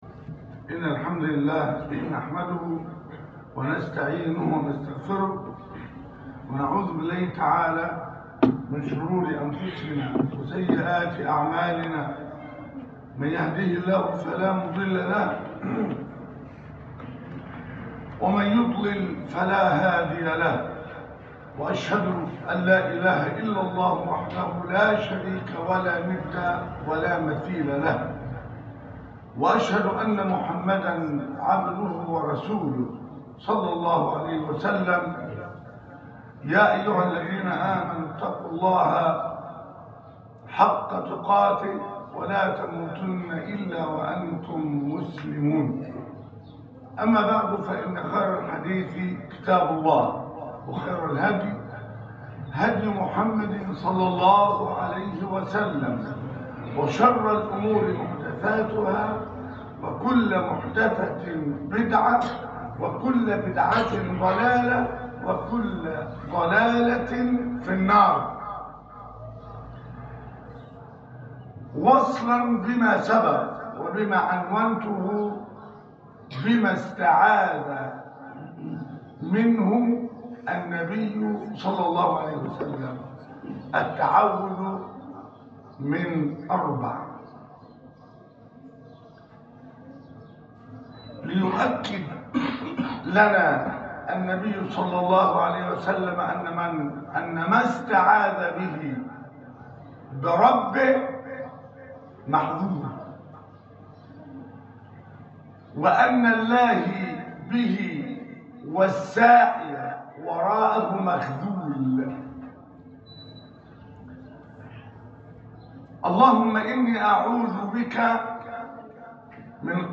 الاستعاذة من أربع - الخطبة الثانية